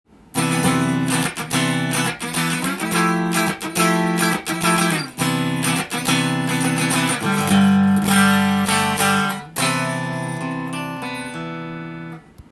このギターの特徴は、シングルコイルの細く綺麗な音で
癖がなく王道のジャキジャキサウンドです。
試しに弾いてみました
クリーントーンでも良い音が出ますし